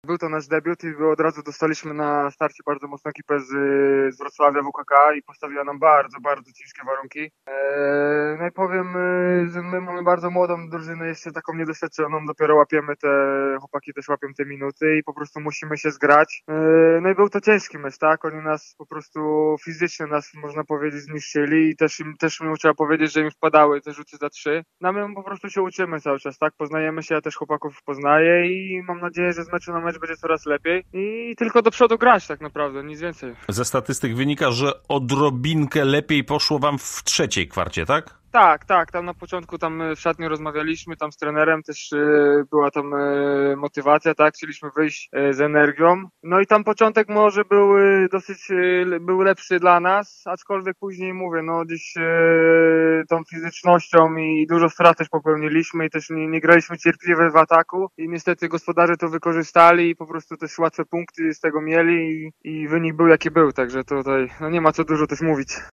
O meczu kilka słów powiedział nam jeden z graczy Aldemedu SKM